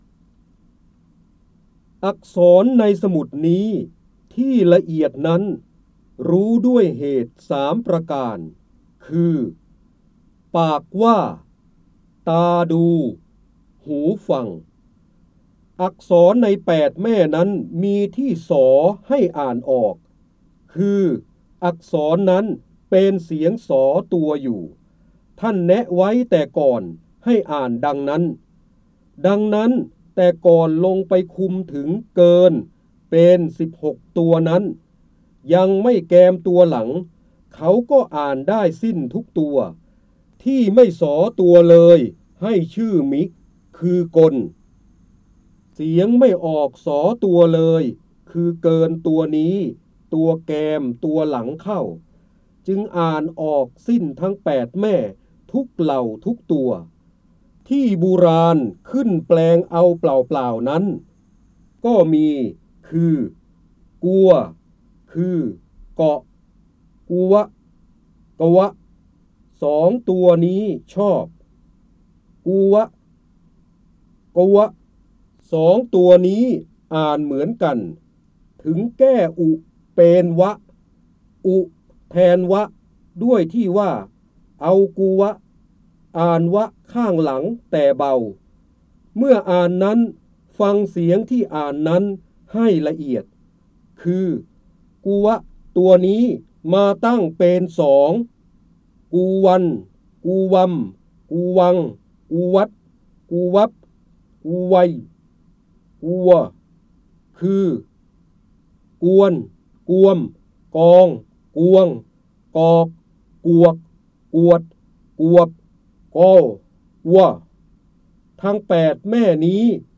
เสียงบรรยายจากหนังสือ